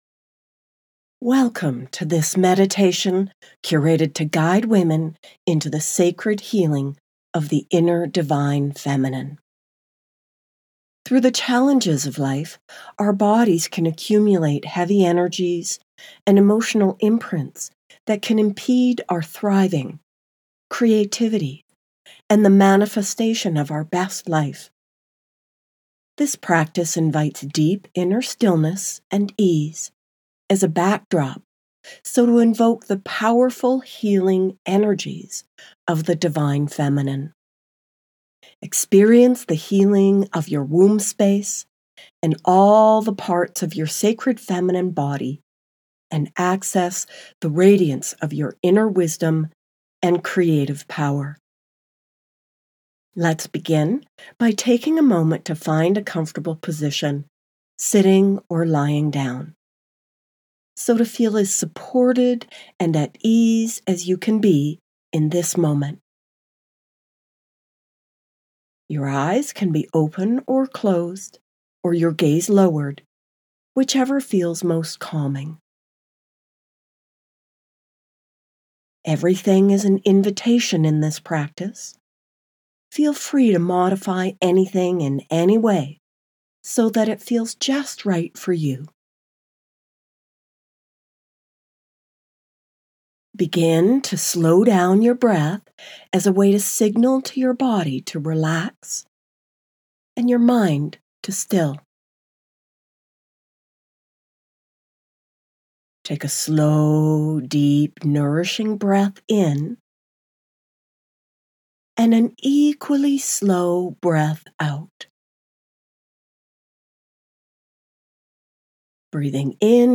Divine Feminine Healing Meditation